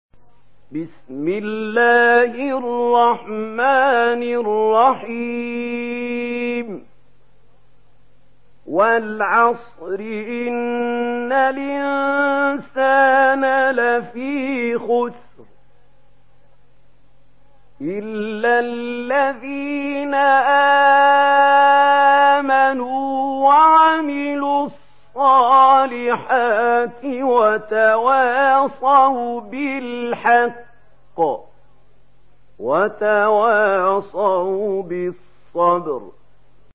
سورة العصر MP3 بصوت محمود خليل الحصري برواية ورش
مرتل ورش عن نافع